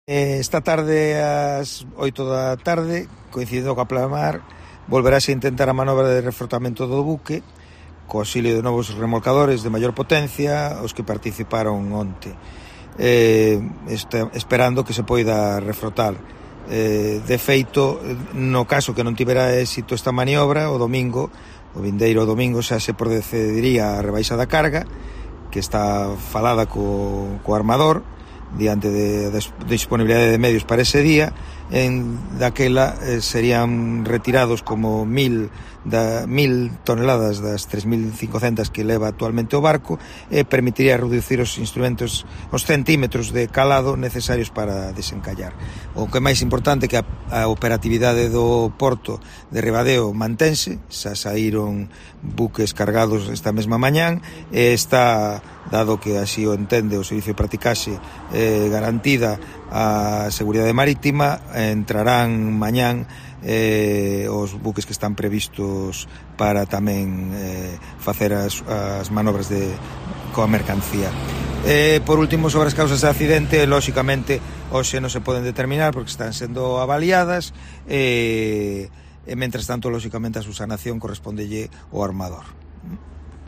El presidente de Portos de Galicia confirmó que intentarán de nuevo la maniobra esta tarde